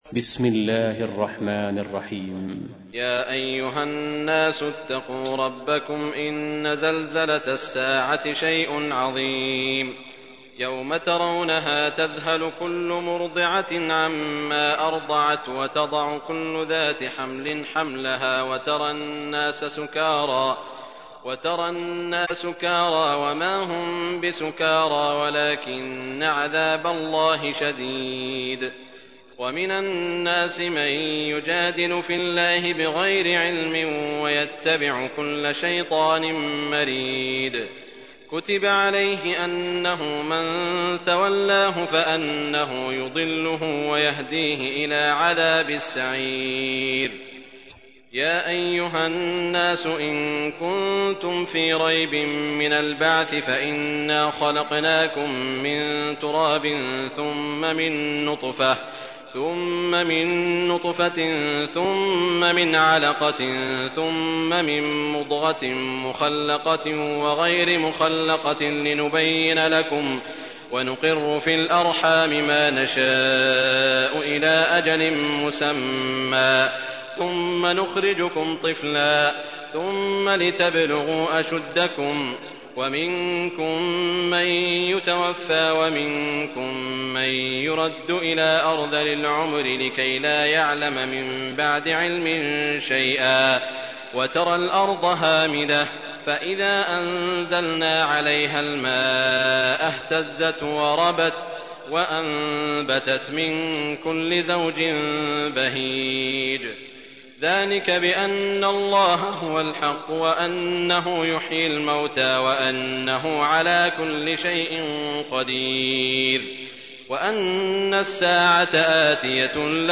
تحميل : 22. سورة الحج / القارئ سعود الشريم / القرآن الكريم / موقع يا حسين